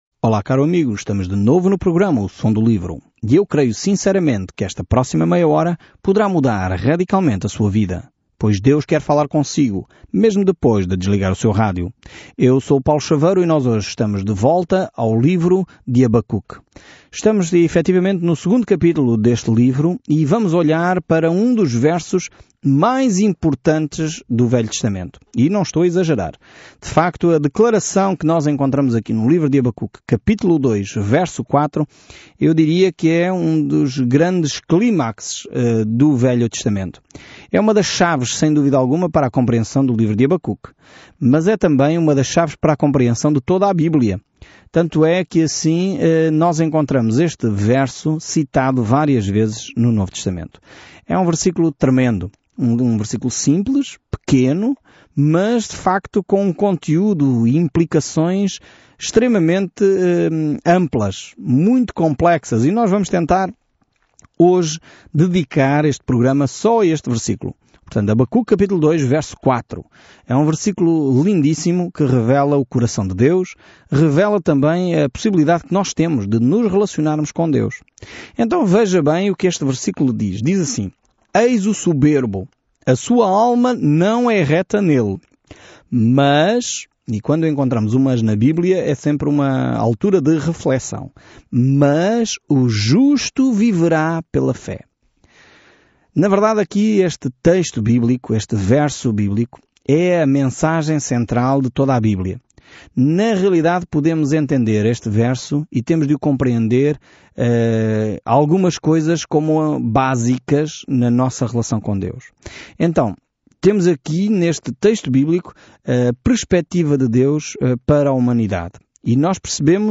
Escritura HABACUC 2:4 Dia 5 Iniciar este Plano Dia 7 Sobre este plano Habacuque pergunta um grande “por que, Deus?” No início de uma série de perguntas e respostas com Deus sobre como ele trabalha em um mundo perverso. Viaje diariamente por Habacuque enquanto ouve o estudo em áudio e lê versículos selecionados da palavra de Deus.